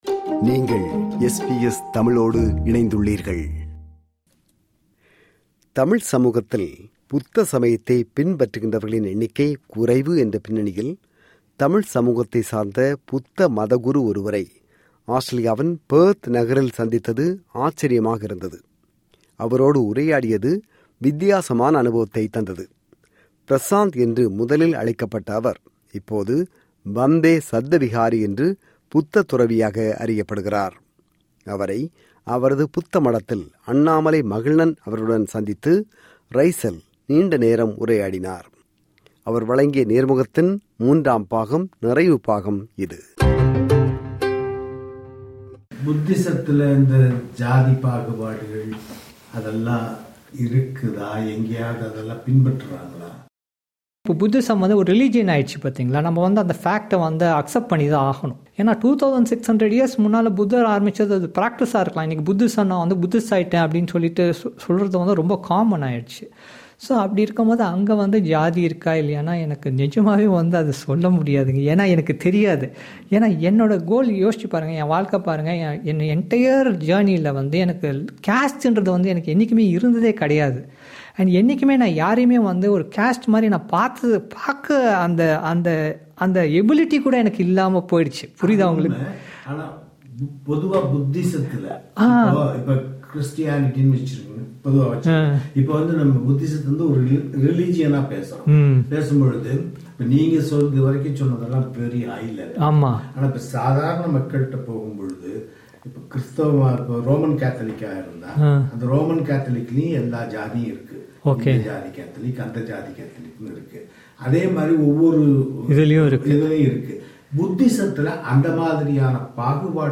அவர் வழங்கிய நேர்முகத்தின் மூன்றாம் (நிறைவு) பாகம்.